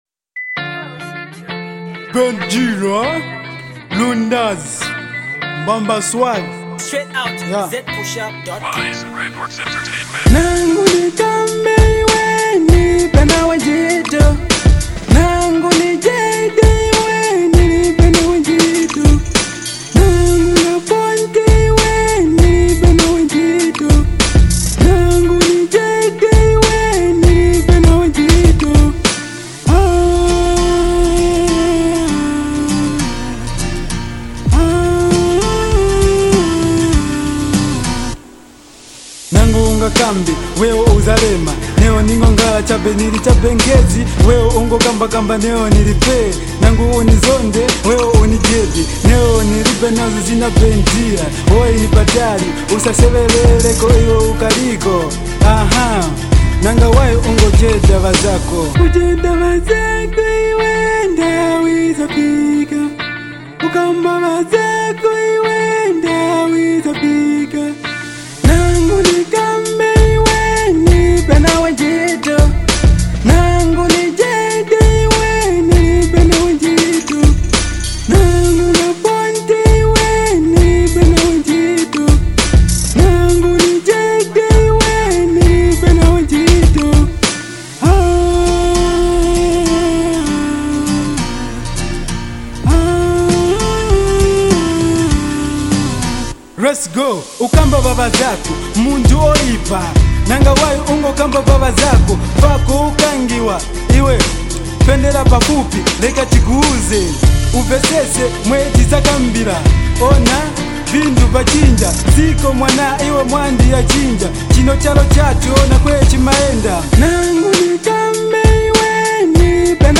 massive self motivative song